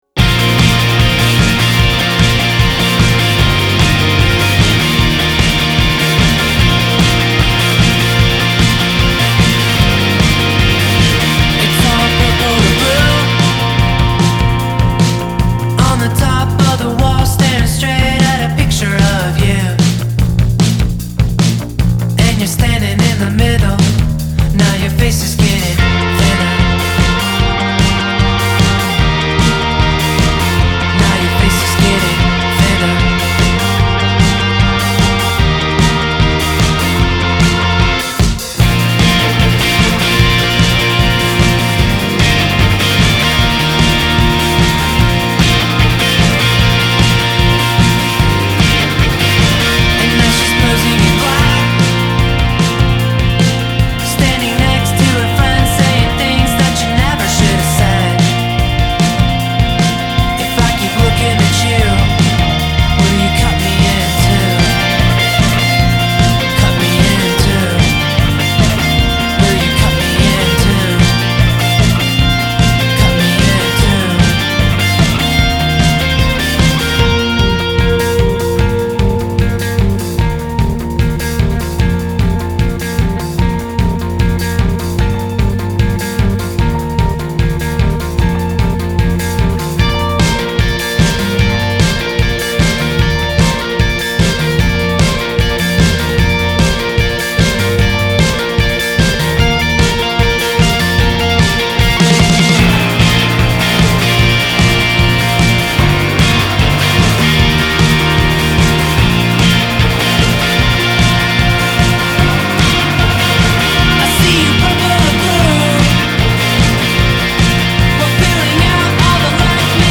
riding the ’80s nostalgia wave
fun, twee-ish rock